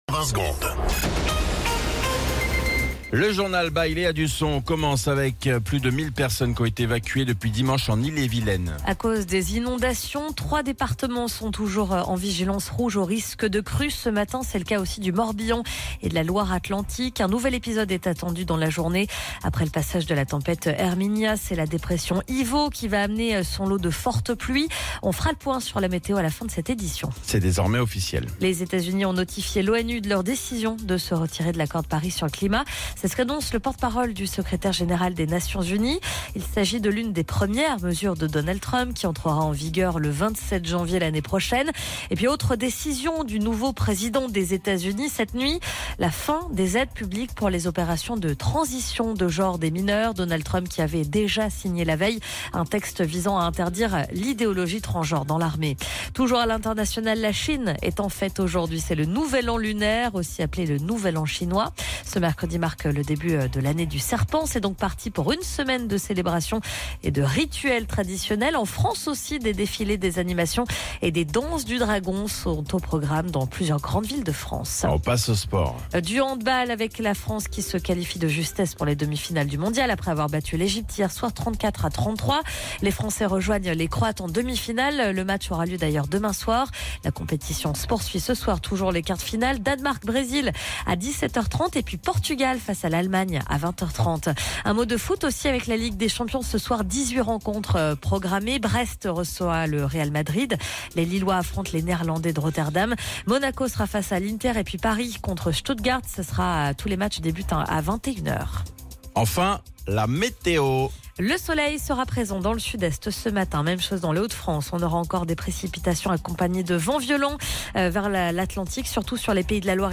Flash Info National 29 Janvier 2025 Du 29/01/2025 à 07h10 .